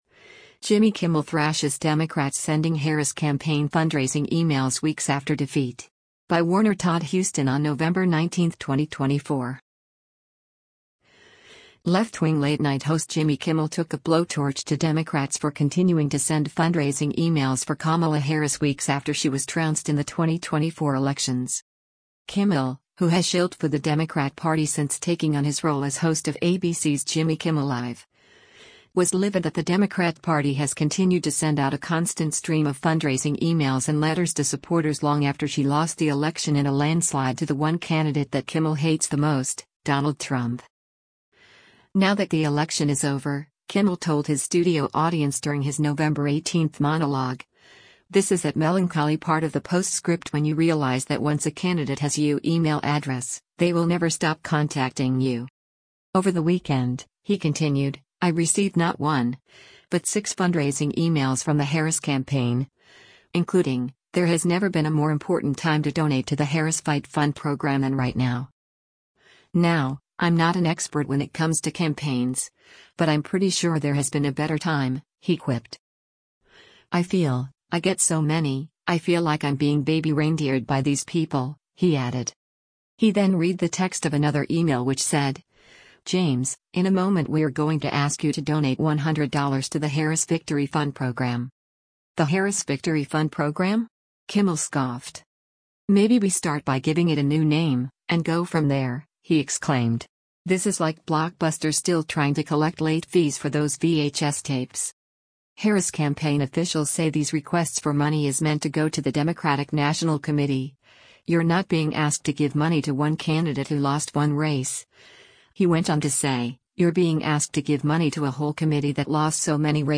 “Now that the election is over,” Kimmel told his studio audience during his November 18 monologue, “this is that melancholy part of the postscript when you realize that once a candidate has you email address, they will never stop contacting you.”